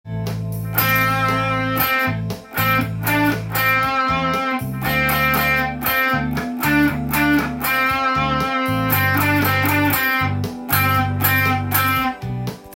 ２音で弾くアドリブ
ドを弾くことに慣れてきたら　次はレの音を入れていきます。
この時も伸ばしたり、休んだり連続で弾くと